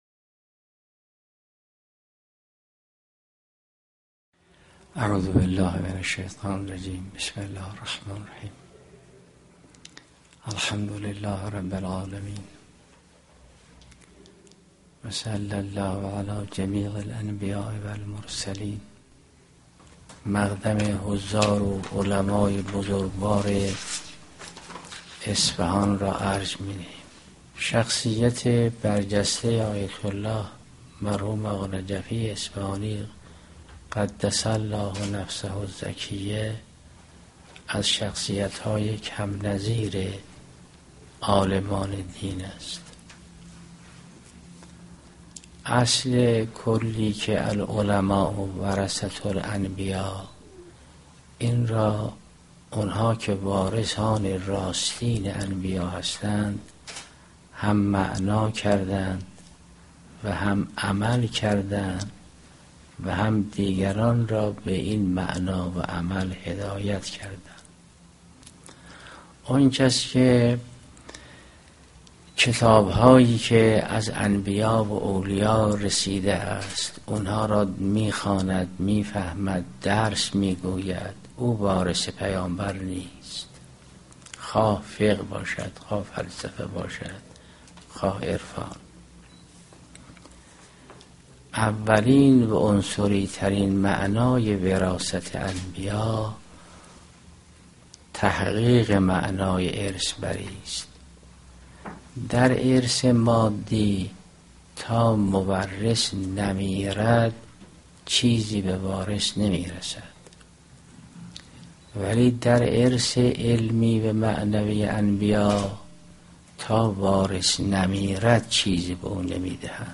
بیانات حضرت آیت الله جوادی آملی دردیداربااعضای همایش بزرگداشت مرحوم آیت الله العظمی آقانجفی(ره) - خانه بیداری اسلامی